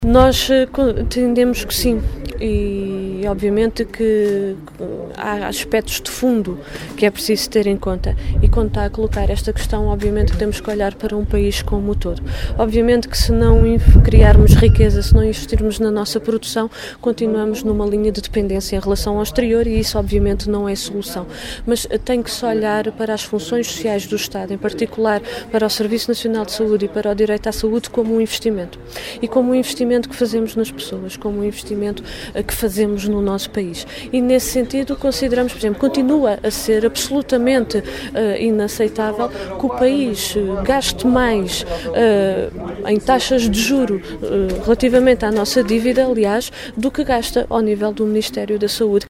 E mesmo que o financiamento para a área da saúde não vá aumentar, a deputada comunista mostra confiança que uma mudança de políticas possa inverter a situação atual.